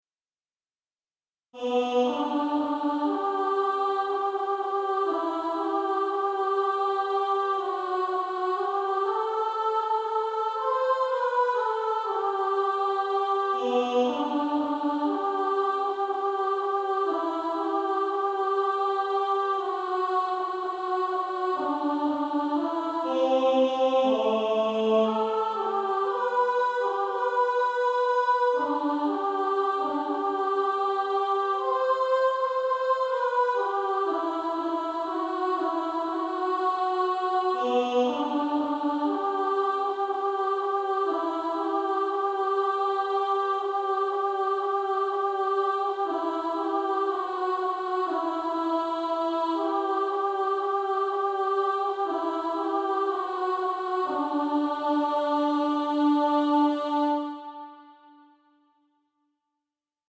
Soprano Track. Alto Track.